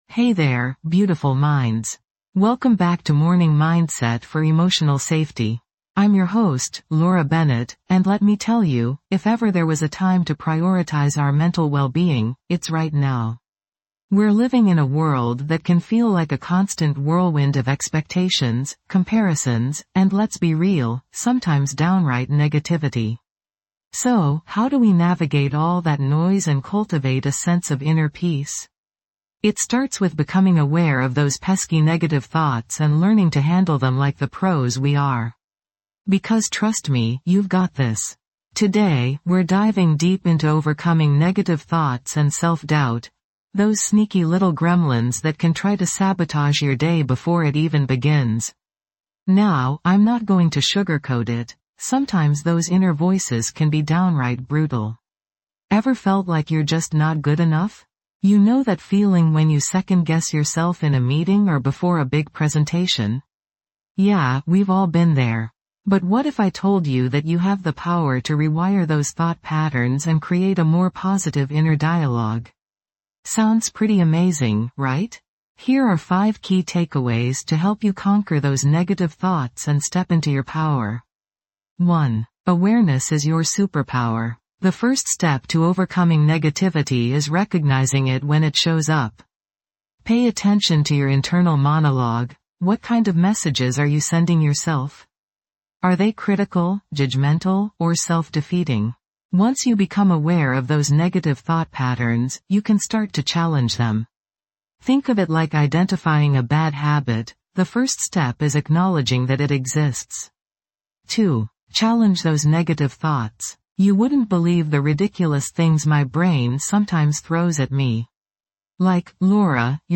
Morning Mindset for Emotional Safety | Start the Day Grounded guides you through mindful practices designed to cultivate a sense of emotional well-being from the moment you wake up. Each episode offers guided meditations, soothing affirmations, and practical tips to help you manage stress, build resilience, and navigate your emotions with grace.